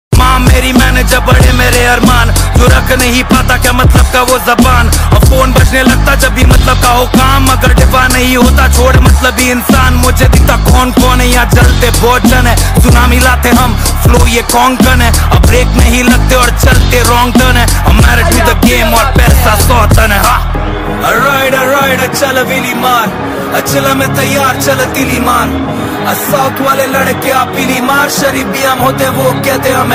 Rap Song Ringtone